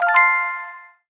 Category 🎮 Gaming
accomplished energy finished game object positive success win sound effect free sound royalty free Gaming